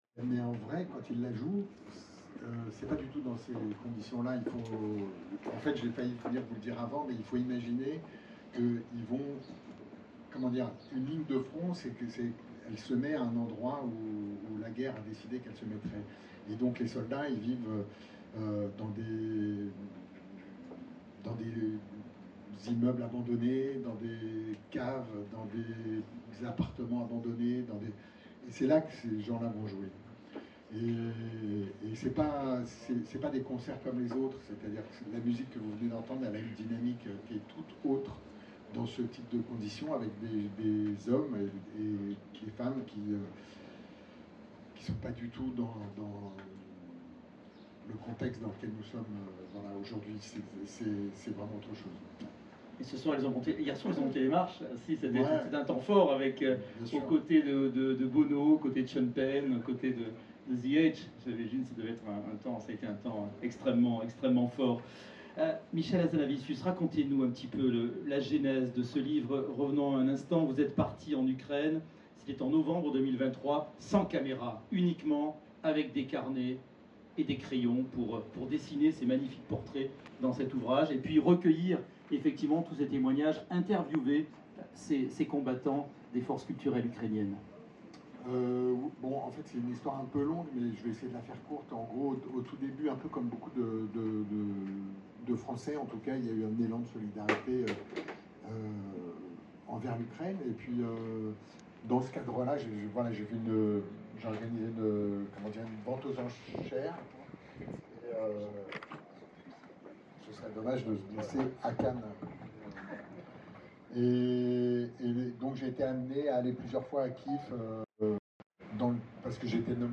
Michel-Hazanavicius-à-la-Fnac-Cannes.mp3